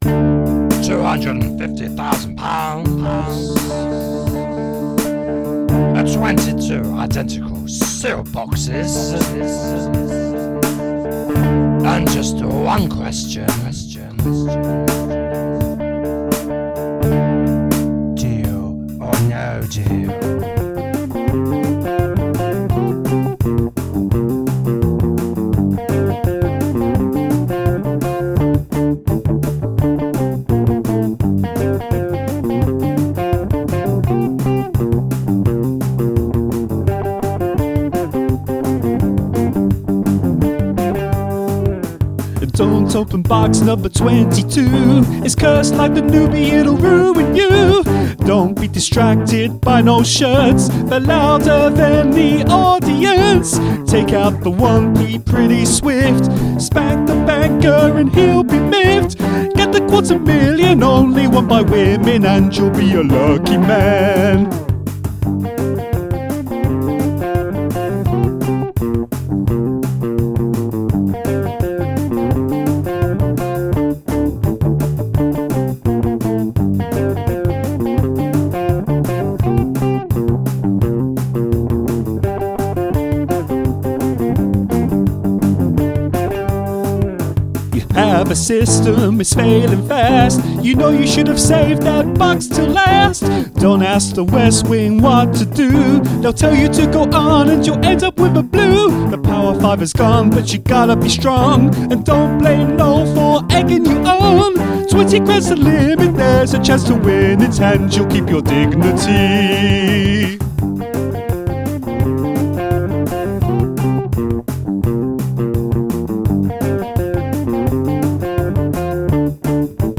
Although my delicious singing intro is good.